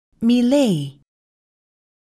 [miléi]